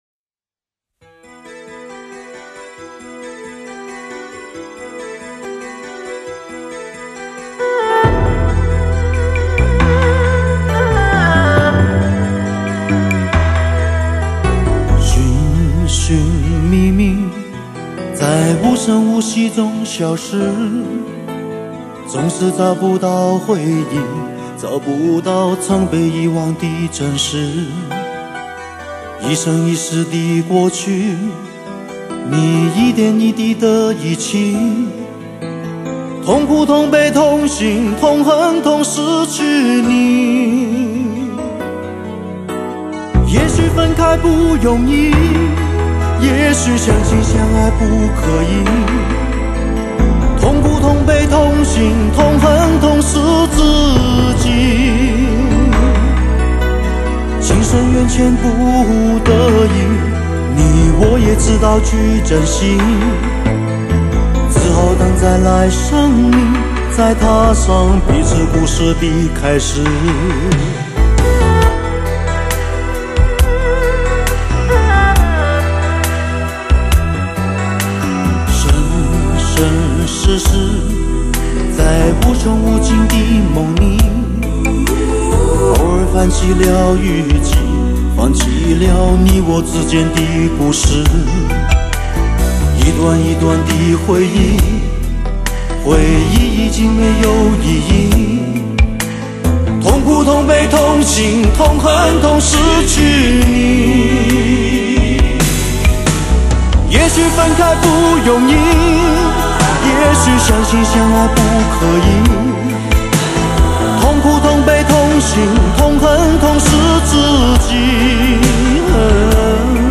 音乐风格: 流行
音场超级宽阔 人声真实 愉悦的类比音质感受 绝妙甜美的音色 极高的声音密度 极强的空气感